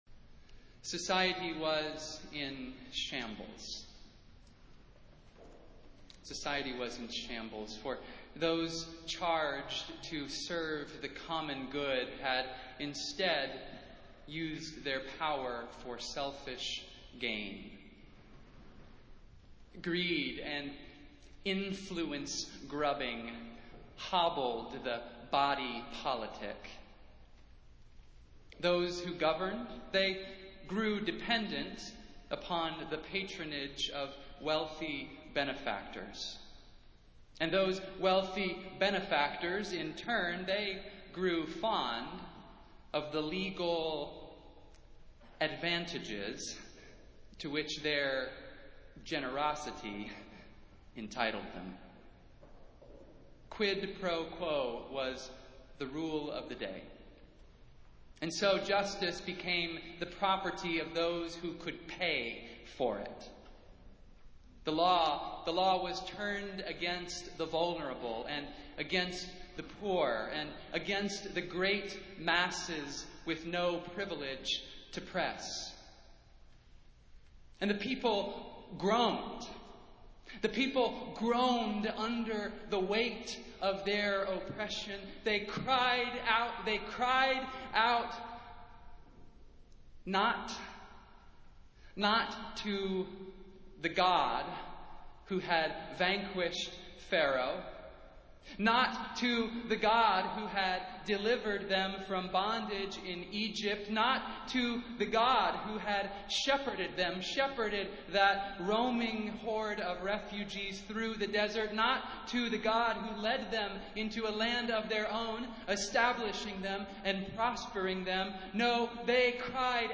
Festival Worship - Reformation Sunday